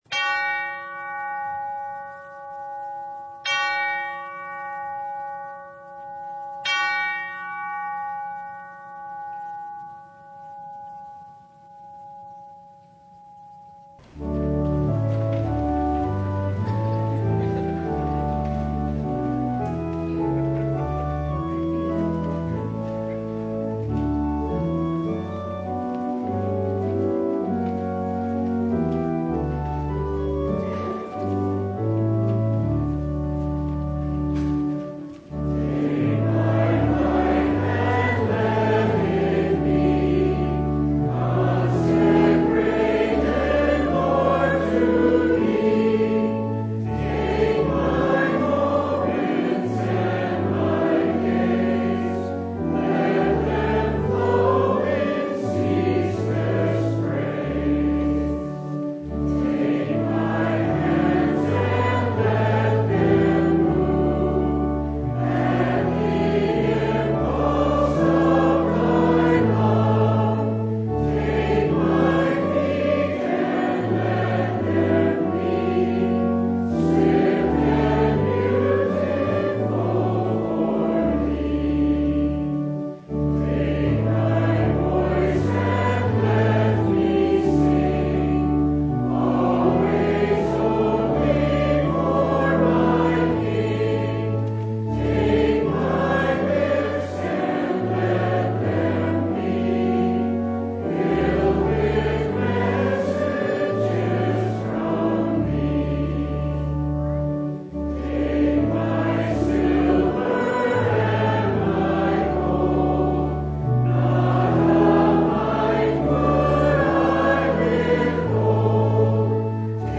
Mark 10:23-31 Service Type: Sunday The love of wealth is a barrier to entering the kingdom of God.